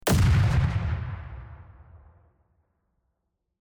sound_earthquake_out.d05d869a..mp3